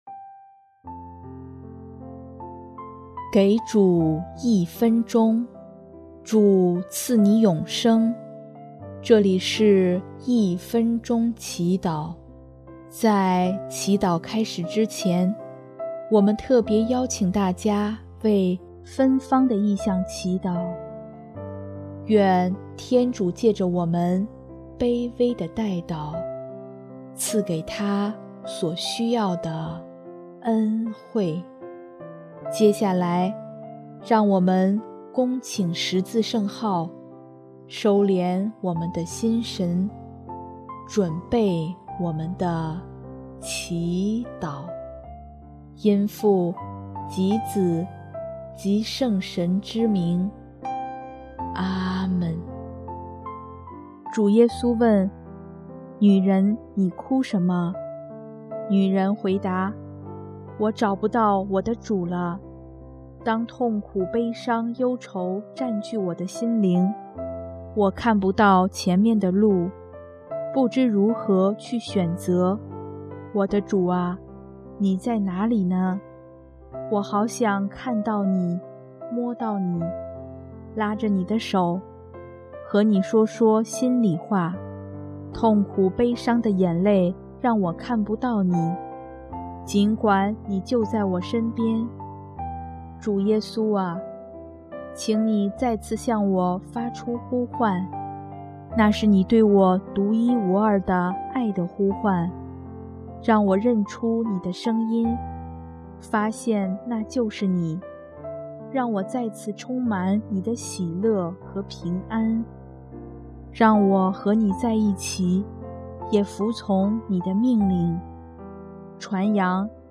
音乐： 第一届华语圣歌大赛参赛歌曲